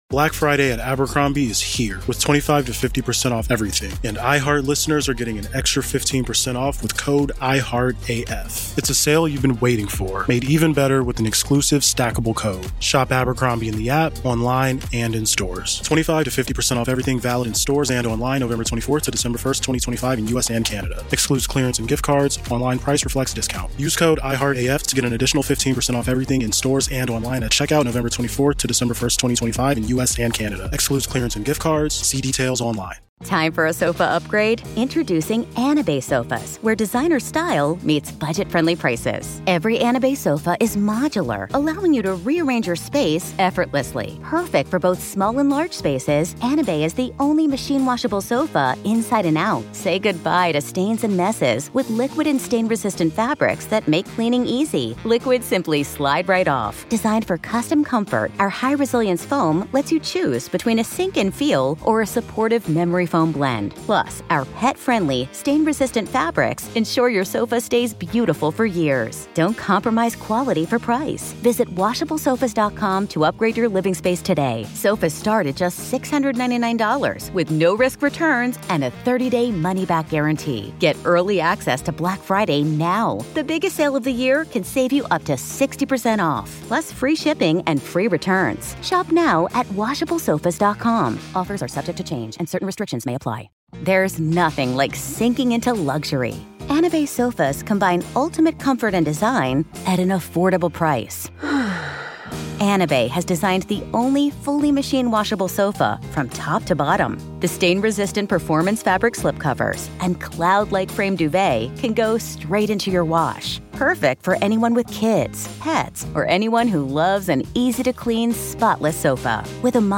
Desecrated Altar Forces Church Demolition! | Real Ghost Stories LIVE!